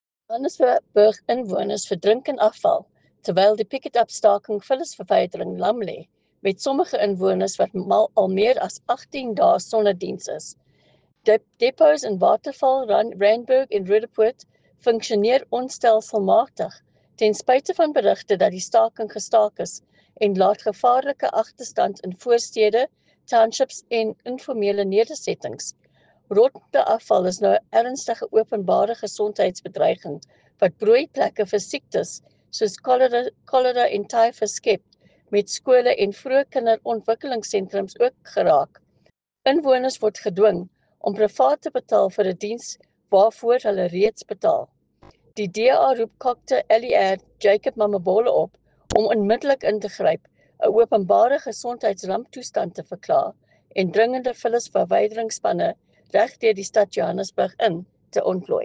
Issued by Leanne De Jager MPL – DA Gauteng Spokesperson for Environment
Afrikaans soundbites by Leanne De Jager MPL.